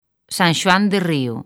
Transcripción fonética
sanˈʃo̯andeˈrio̝